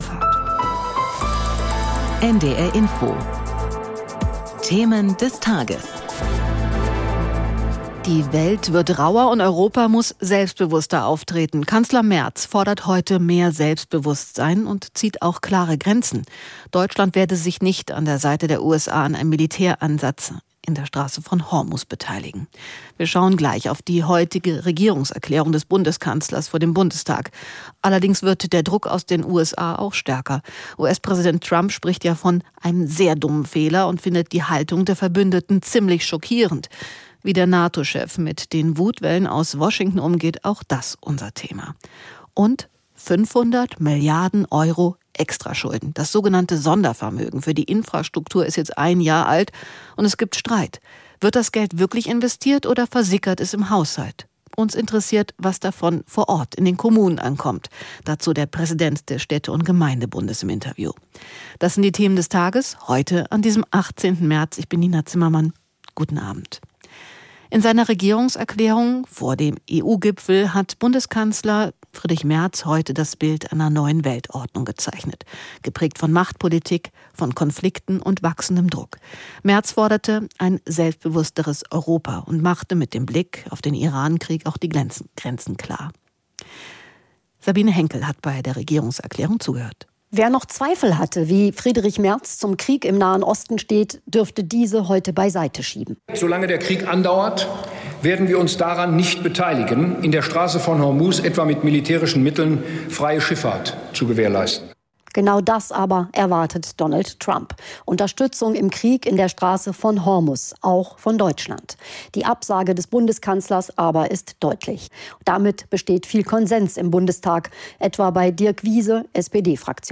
In einer angespannten Weltlage hat Kanzler Merz heute eine Regierungserklärung vor dem EU-Gipfel abgegeben.